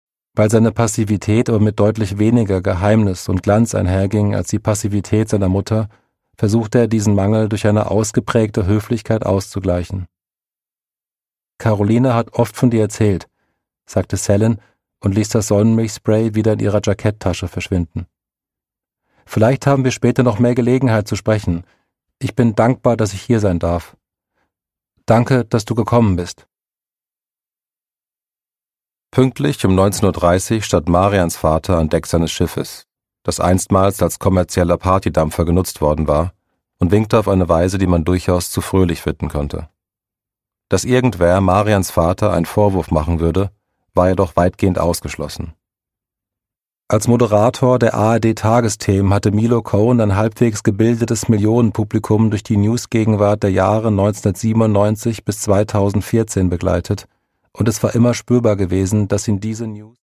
Produkttyp: Hörbuch-Download
Gelesen von: Leif Randt